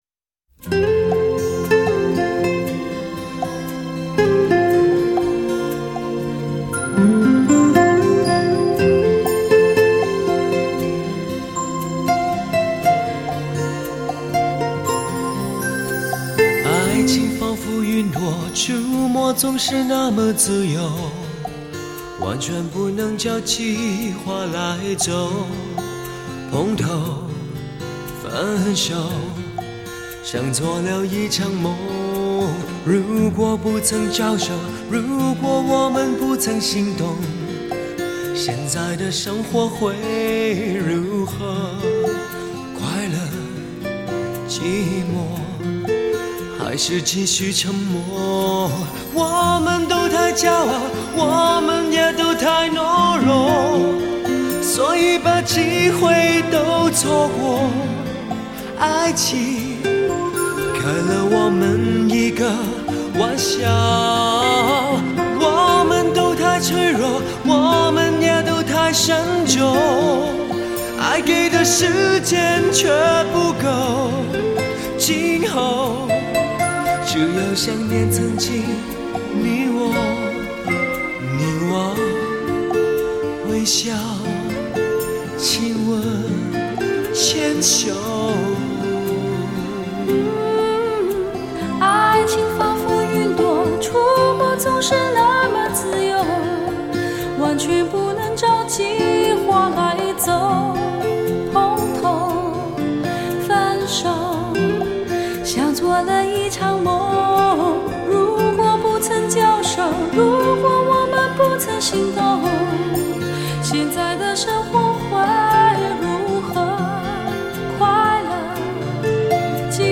曲风没有过去的低沉成熟，换成轻松调皮又略带迷惑憧憬的风格。